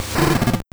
Cri de Marcacrin dans Pokémon Or et Argent.